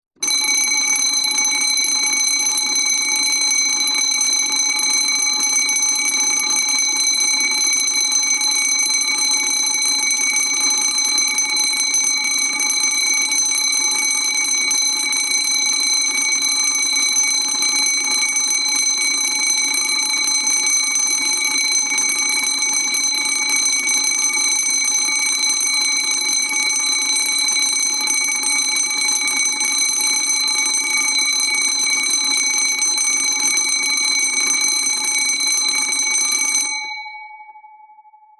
Tiếng chuông báo cháy mp3 (Nhạc Chuông)